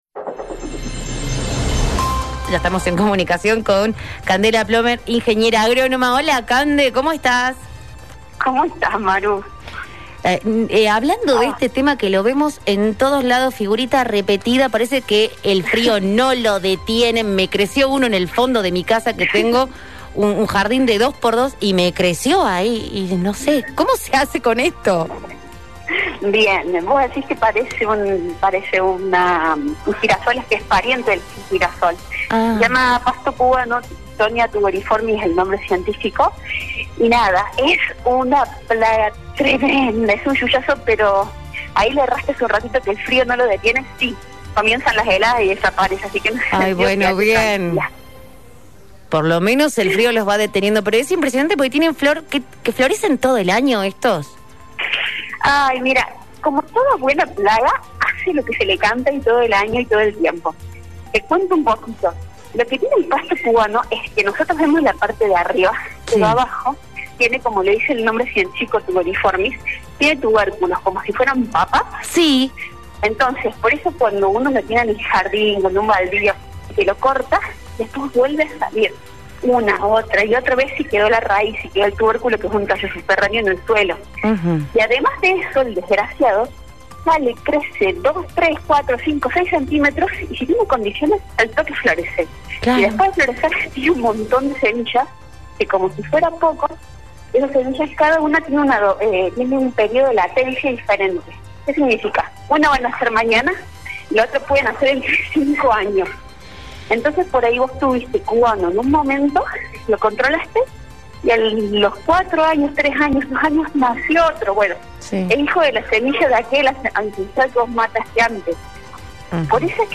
En dialogo con la ingeniera